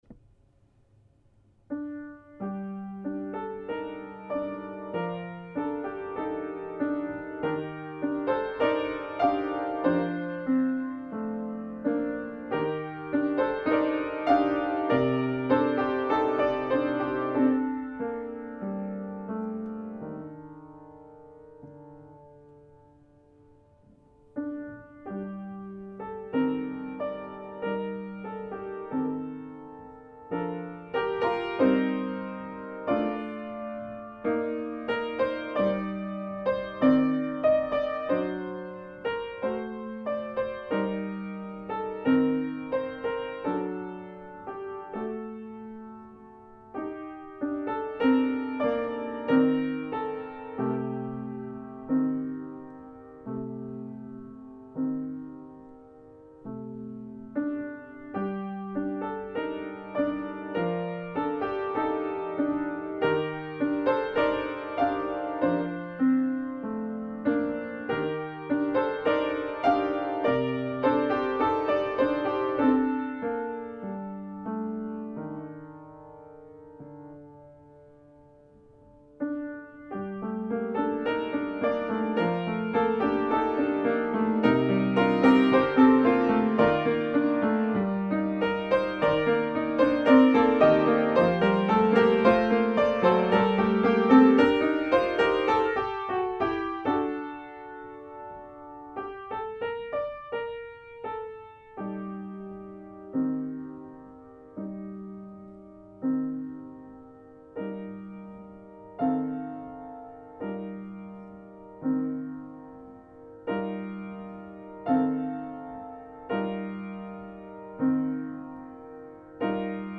This is one of those, and while it may not be familiar to all, it has always struck me as being powerful in its haunting melody and simple words.
This is an old German carol thought to go back to the 17th century, although the earliest printed version is from the mid 1800s.  It is the traditional text about Mary walking in thorns from which a rose will eventually emerge.